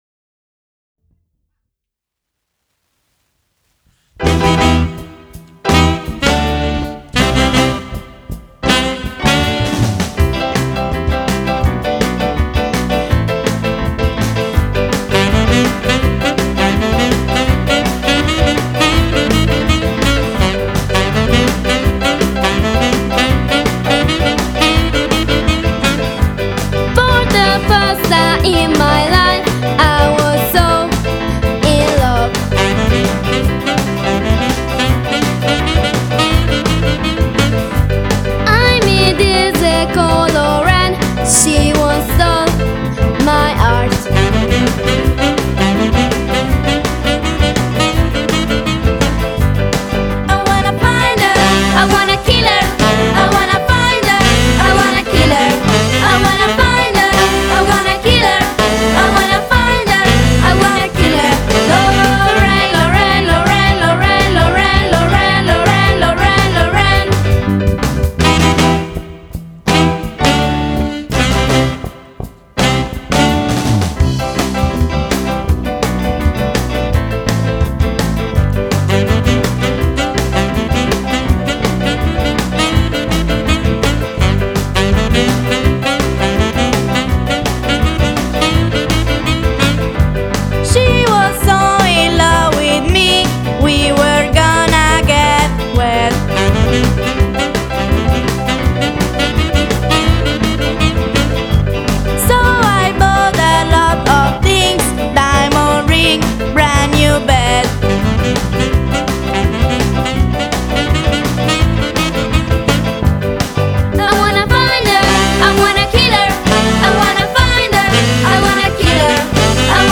Allievi di musica d'insieme 2009-2010 - Scuola di musica Blue Note